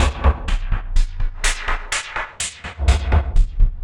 tx_perc_125_splosh.wav